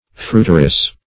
Fruiteress \Fruit"er*ess\, n. A woman who sells fruit.